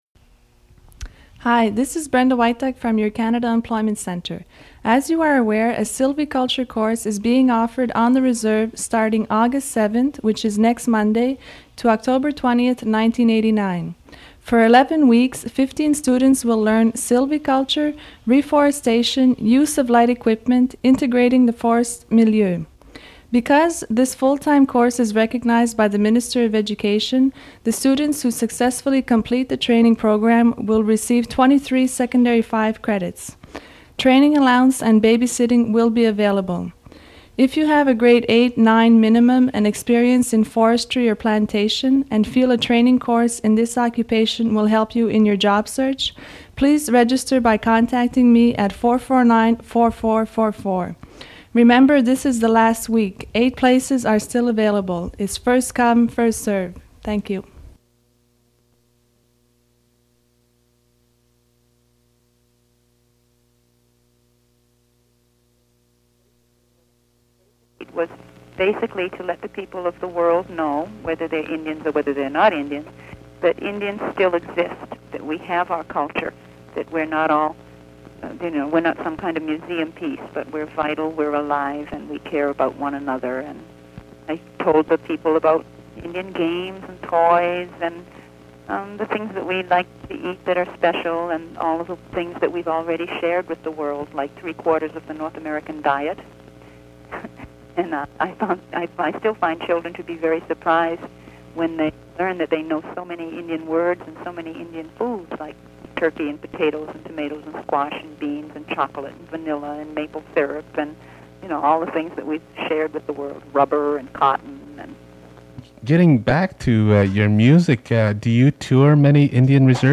Fait partie de Formation en foresterie et entretien avec Buffy Sainte-Marie